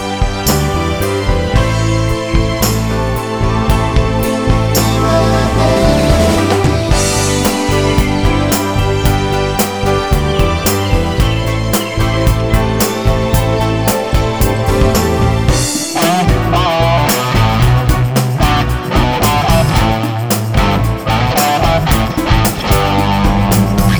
Live Version Pop (1980s) 4:07 Buy £1.50